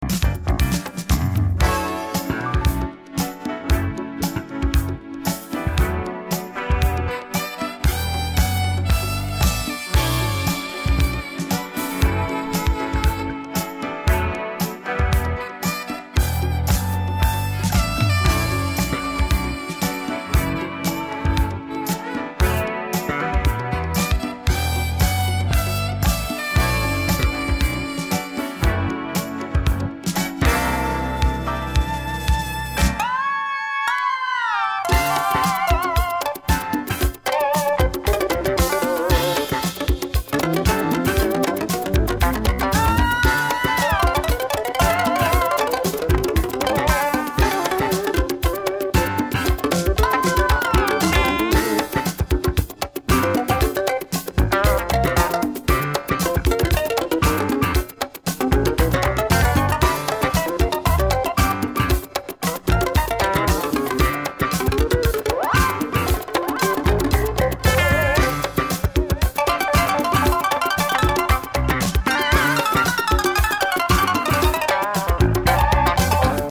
soul 7"